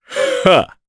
Gau-Vox_Happy1_jp.wav